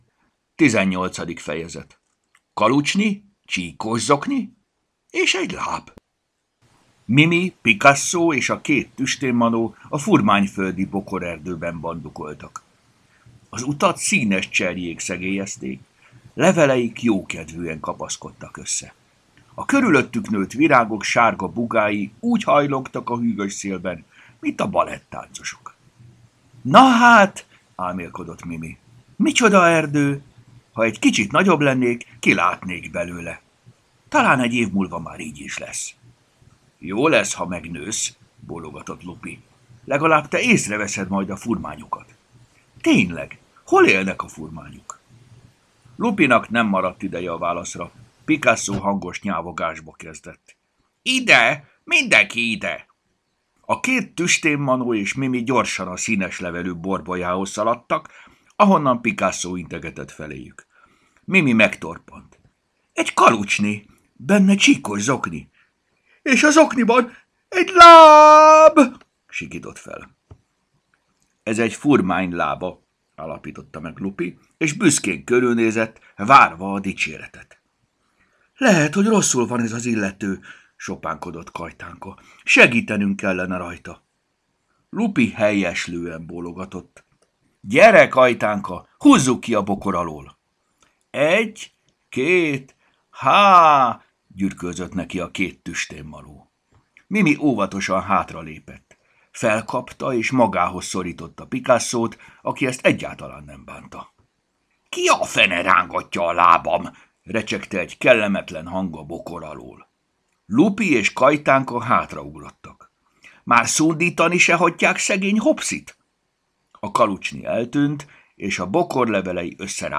Hangos mese: Kalucsni, csíkos zokni és egy láb Mindet meghallgatom ebből a folyamból!